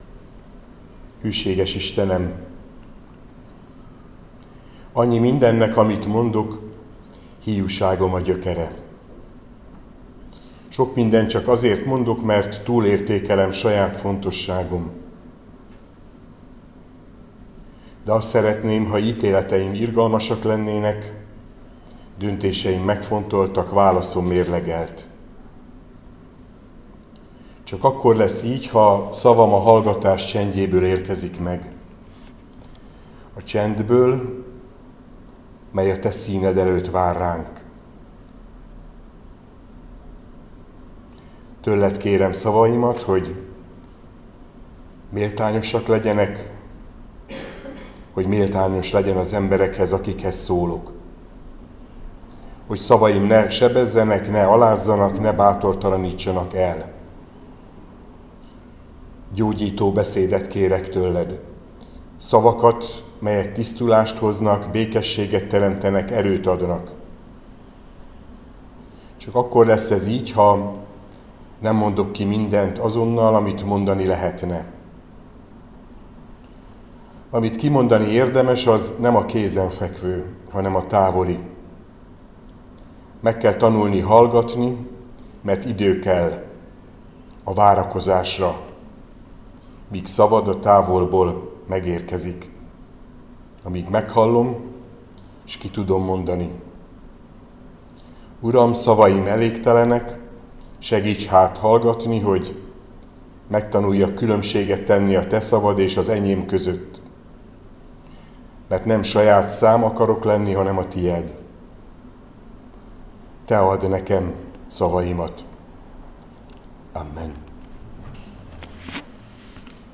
Áhítat, 2024. május 28.
2Kir 5. Balog Zoltán püspök